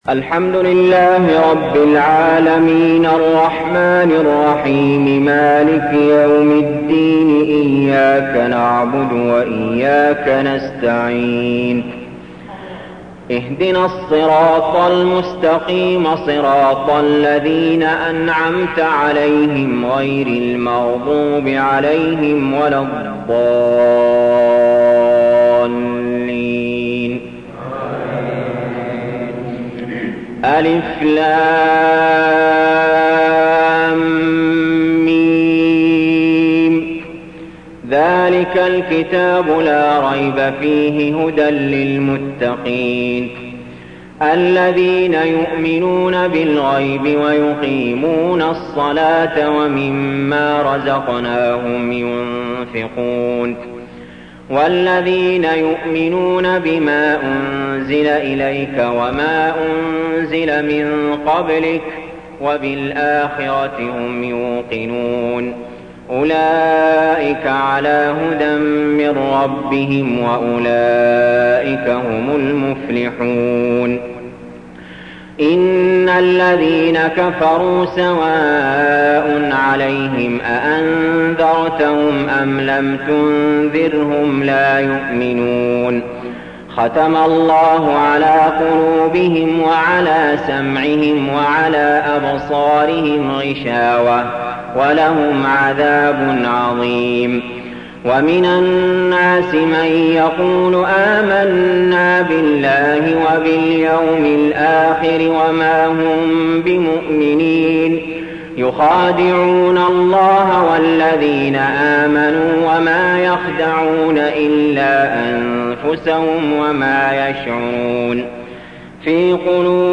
المكان: المسجد الحرام الشيخ: علي جابر رحمه الله علي جابر رحمه الله البقرة